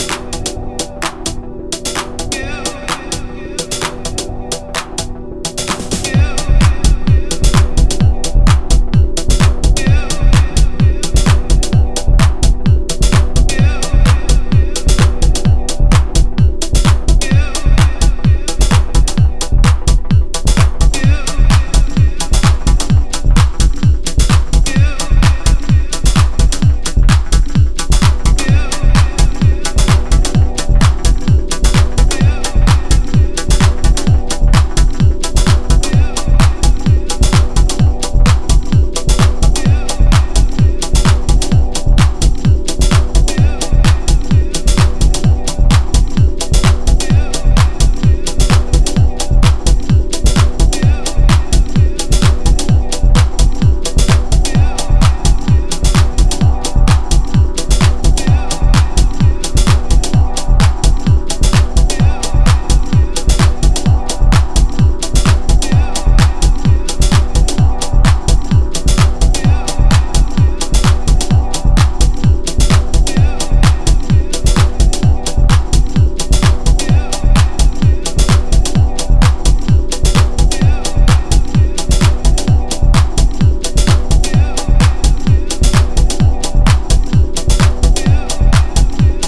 A catchy tech house tune, “step in” if you can!